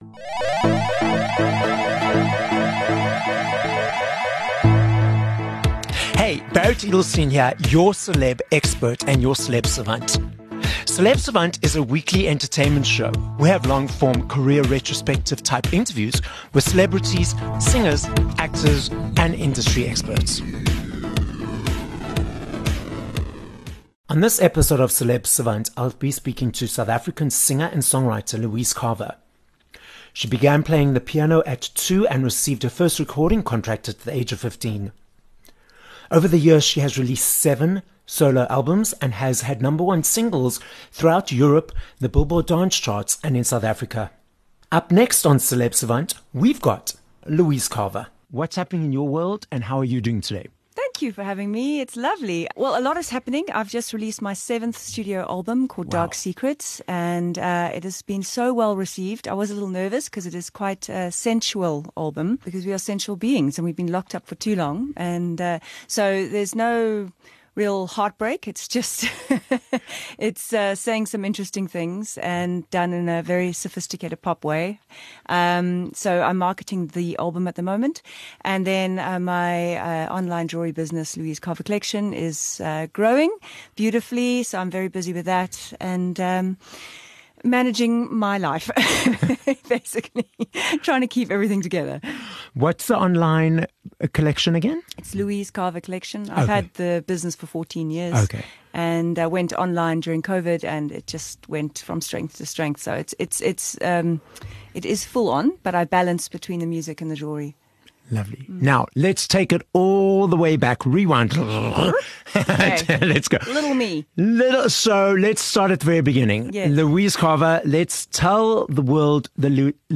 2 Feb Interview with Louise Carver